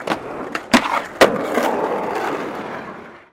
Звуки скейтборда
Звук резкого маневра на скейтборде